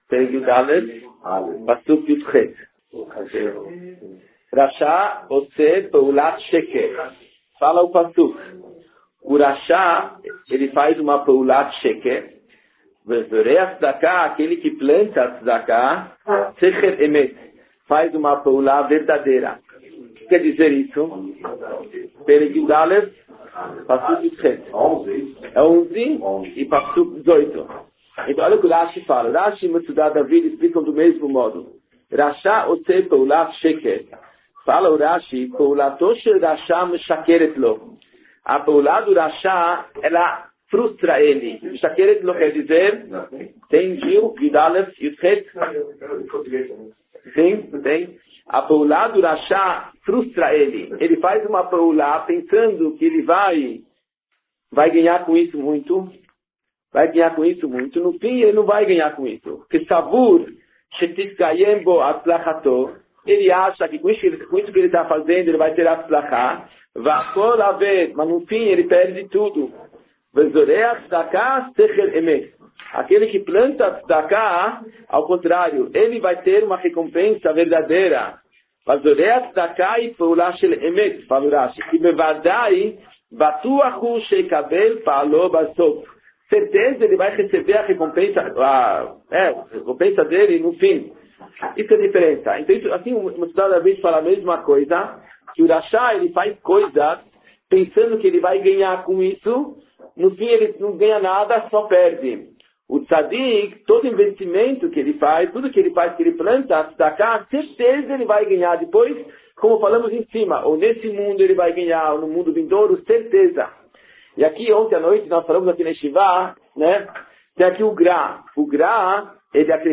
Portugues Baixa Qualidade do Audio: Shiur antigo remasterizado – Desculpe a baixa qualidade.